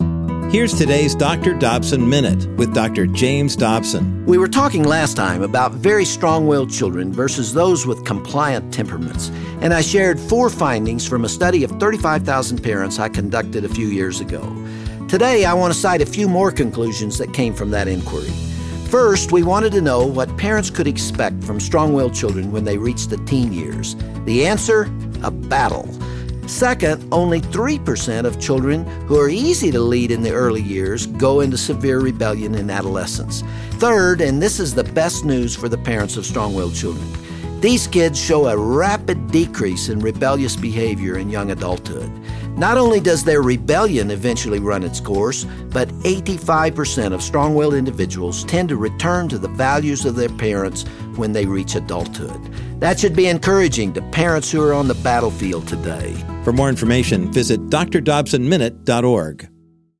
Dr. Dobson talk about what parents can expect from children with different temperaments as they become teenagers.